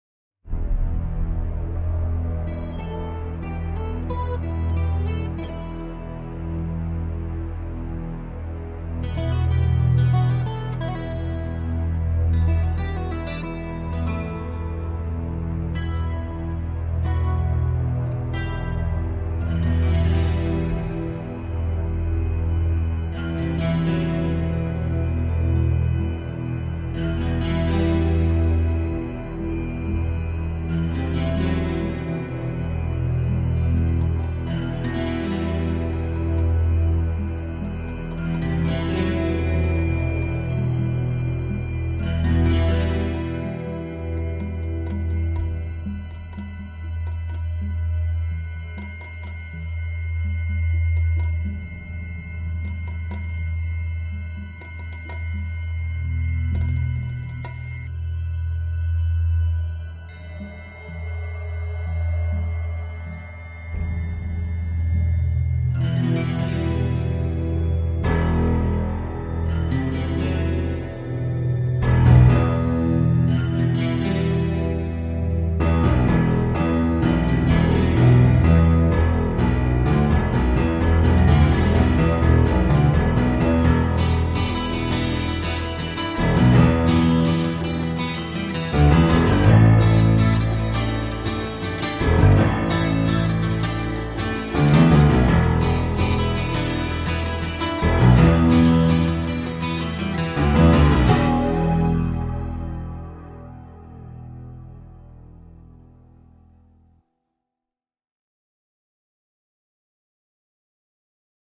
dont la guitare
portée par une guitare acoustique